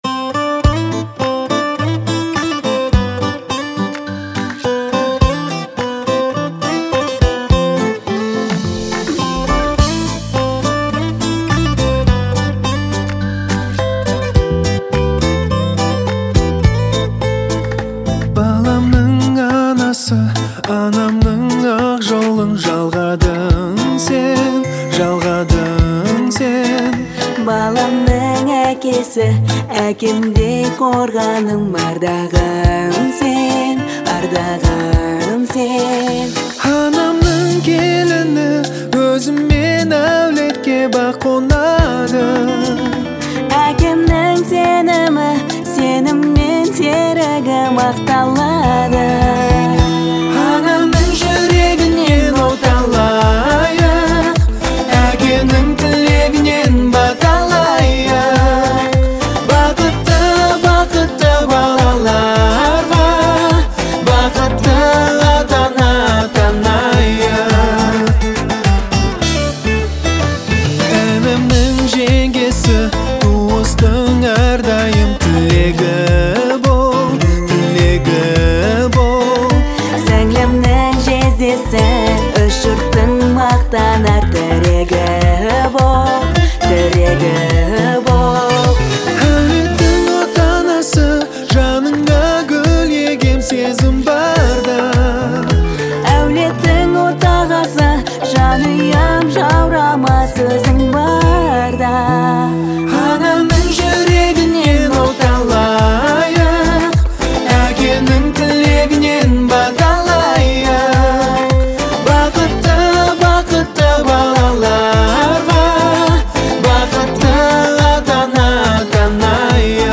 это яркий образец казахской поп-музыки